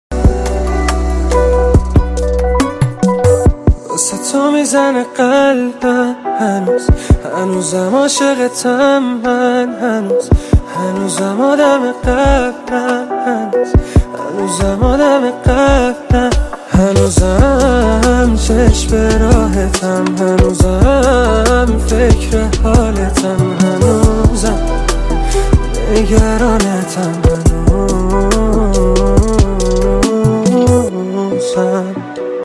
فضای احساسی و عاشقانه دارند.
حرفه: خواننده پاپ
پیانو و گیتار